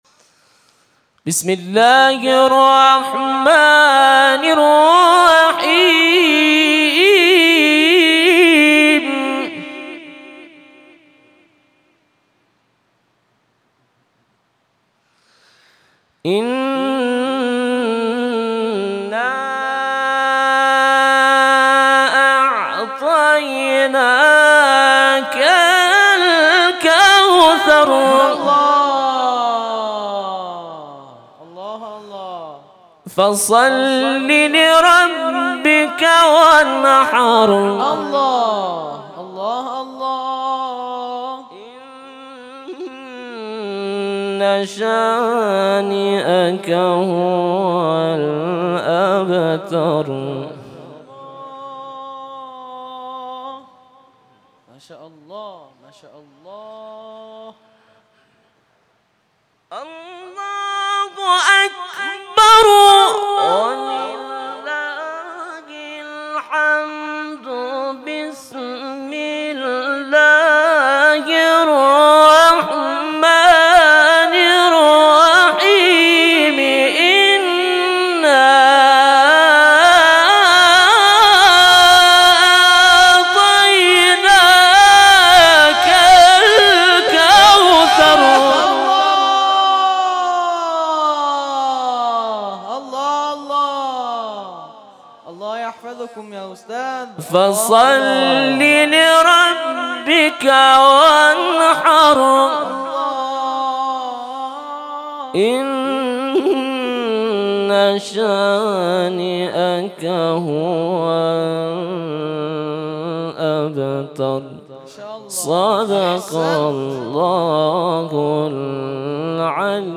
تلاوت قرآن ، سوره کوثر